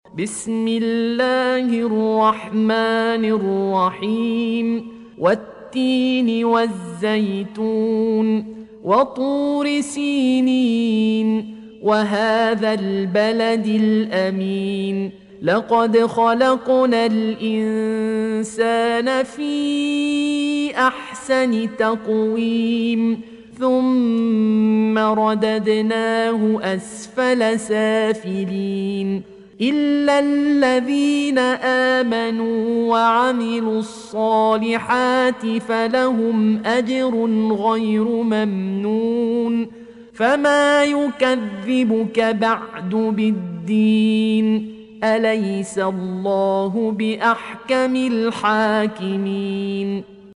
Audio Quran Tarteel Recitation
Surah Repeating تكرار السورة Download Surah حمّل السورة Reciting Murattalah Audio for 95. Surah At-Tin سورة التين N.B *Surah Includes Al-Basmalah Reciters Sequents تتابع التلاوات Reciters Repeats تكرار التلاوات